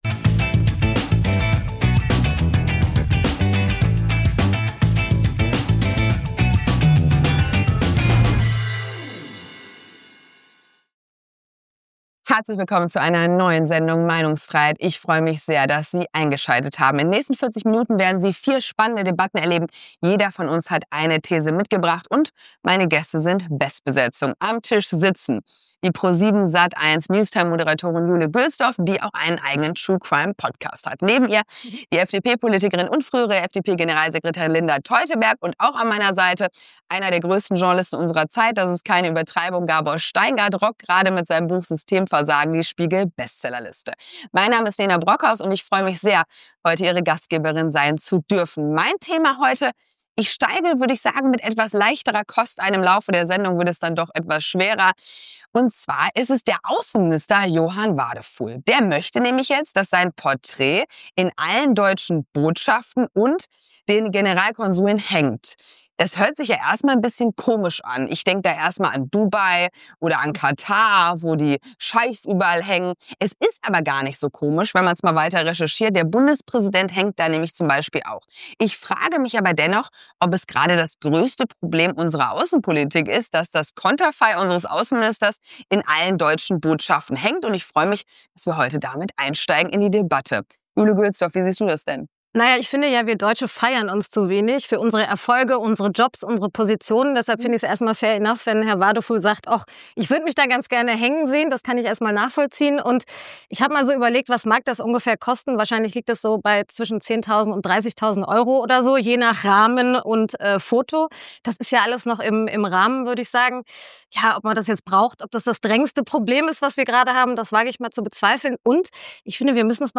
Beschreibung vor 3 Monaten Ausgebauter Sozialstaat, Rentenpaket und keine Impulse für die Produktivität im Land: Die Gründe für Gabor Steingarts Enttäuschung über Merz häufen sich zunehmend. In der neuen Folge von „Meinungsfreiheit“ debattiert er mit Nena Brockhaus, Jule Gölsdorf und Linda Teuteberg über die Frage, inwiefern der Kanzler den Abstieg Deutschlands beschleunigt.
Das Konzept sieht vor, dass jeder Gast eine eigene These mit in die Sendung bringt und diese mit den anderen Gästen und der Moderation diskutiert.